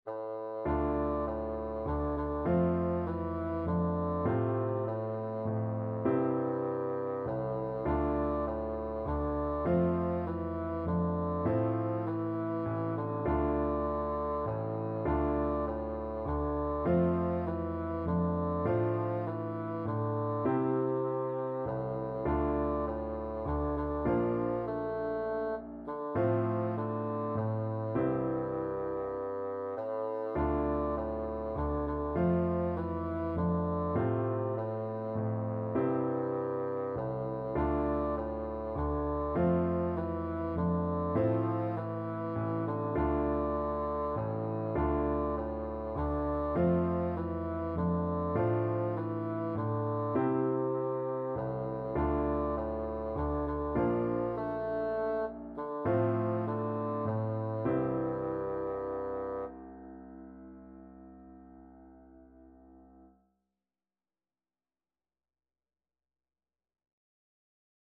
Traditional Trad. I wonder as I wander (Appalacian carol) Bassoon version
6/8 (View more 6/8 Music)
F major (Sounding Pitch) (View more F major Music for Bassoon )
Traditional (View more Traditional Bassoon Music)